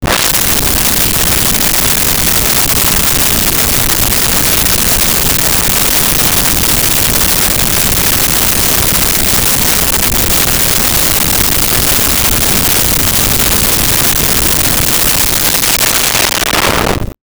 Underwater 01
Underwater 01.wav